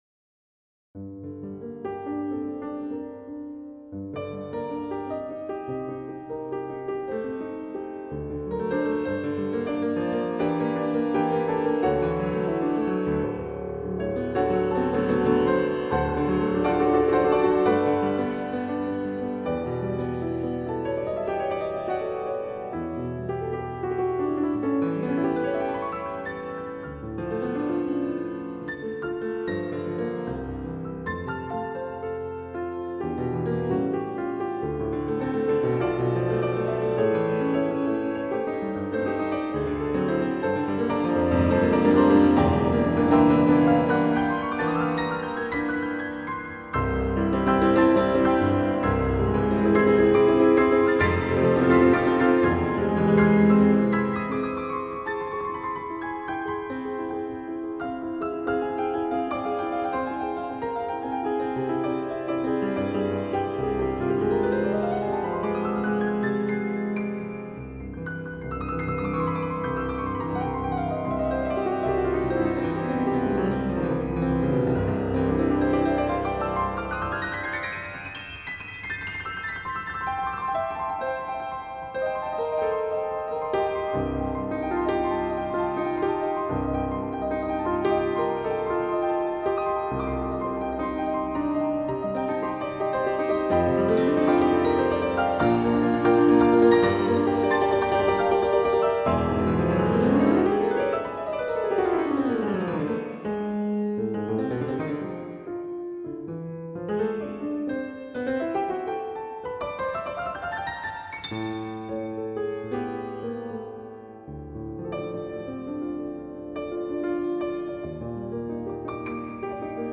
piano
Ballade virtuose numéro 1 pour piano juin 19, 2010 Obtenir le lien Facebook X Pinterest E-mail Autres applications C'est ma première composition de Ballade aux modulations multiples.
Ballade virtuose pour piano numéro 1 Piano classique Obtenir le lien Facebook X Pinterest E-mail Autres applications